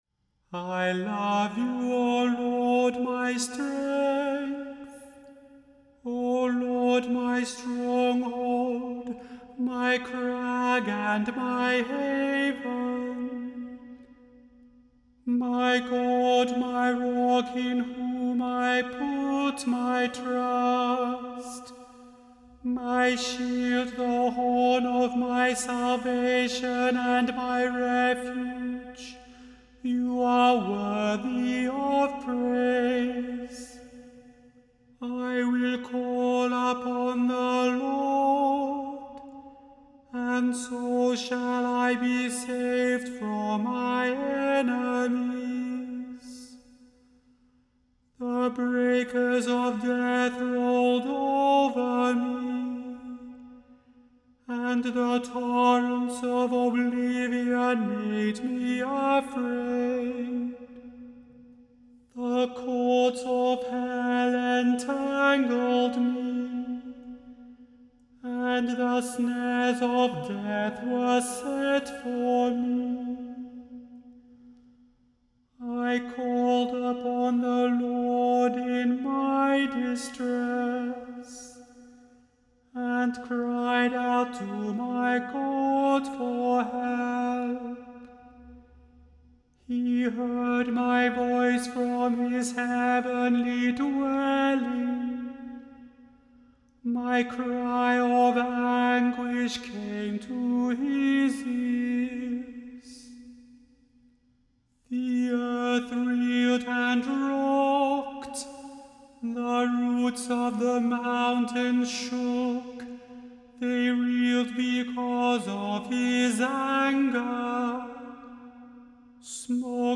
The Chant Project – Psalm 18 vs 1-20 – Immanuel Lutheran Church, New York City